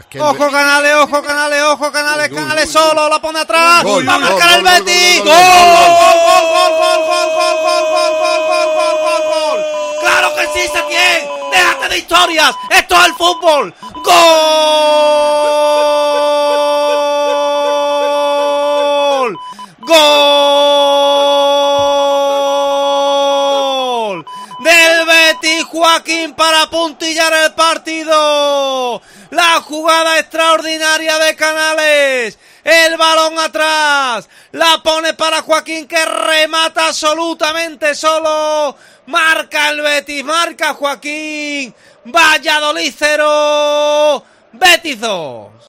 Así sonó el triunfo del Betis ante el Valladolid en Cope Más Sevilla